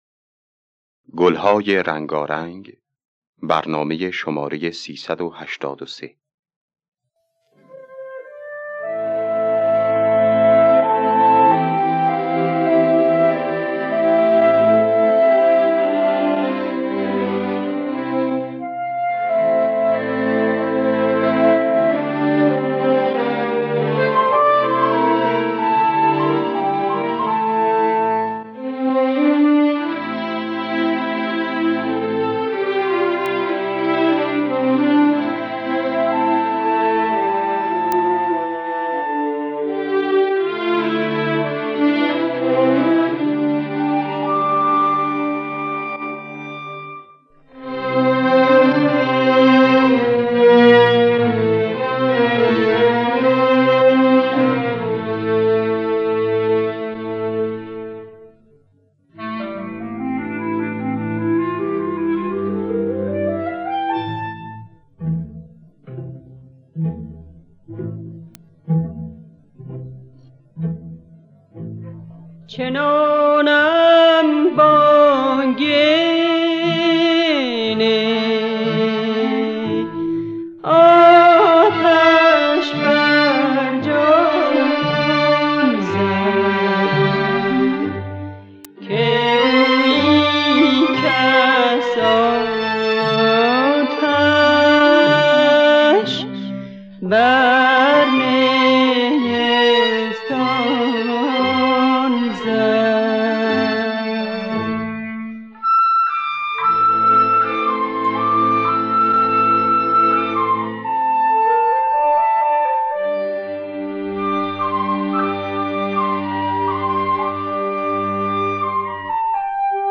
دانلود گلهای رنگارنگ ۳۸۳ با صدای پوران، عبدالوهاب شهیدی در دستگاه دشتی.